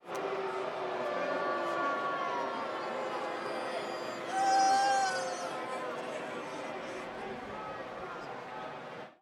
crowd-boos.wav